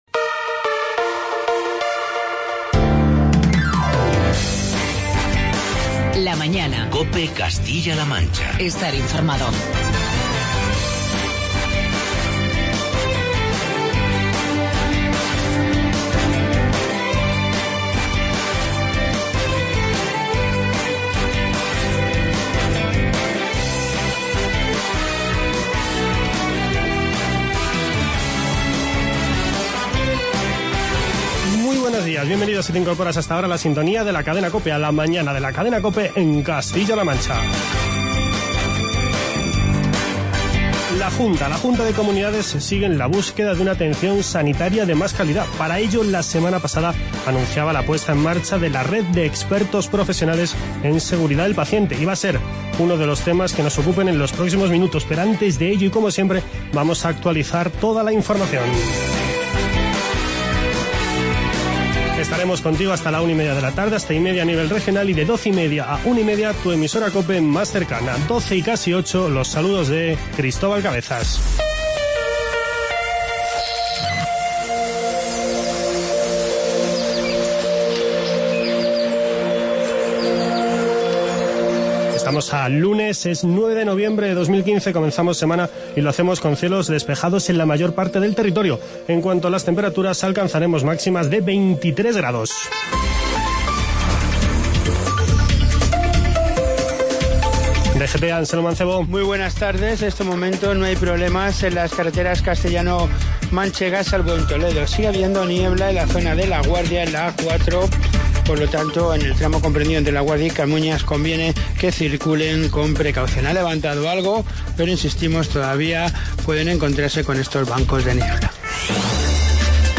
Charlamos con Rodrigo Gutiérrez, director general de Calidad en la Asistencia Sanitaria, y con Araceli Martínez, directora del Instituto de la Mujer